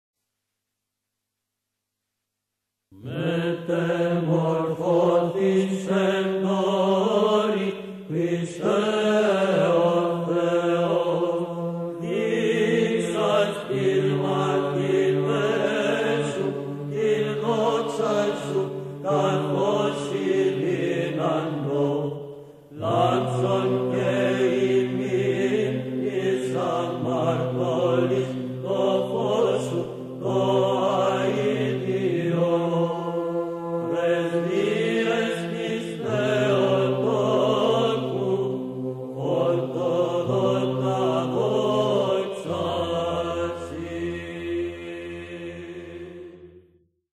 Ἀπολυτίκιον. Ἦχος βαρύς.